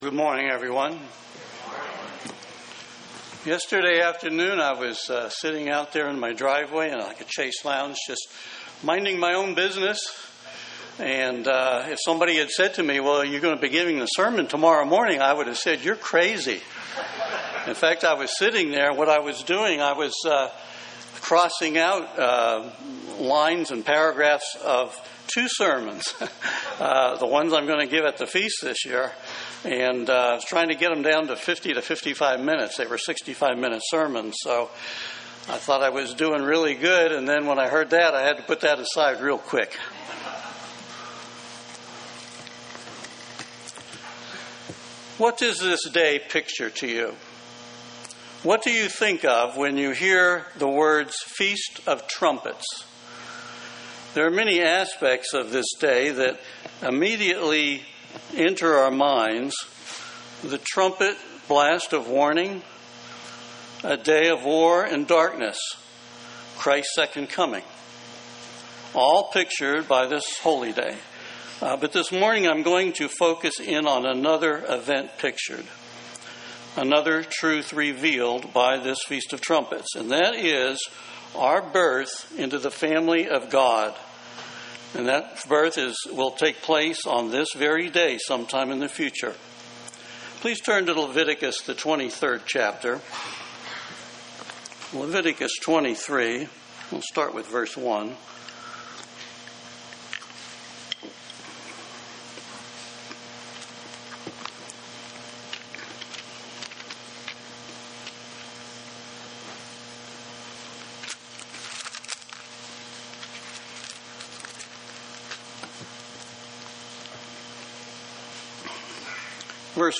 UCG Sermon Studying the bible?
Given in Columbus, OH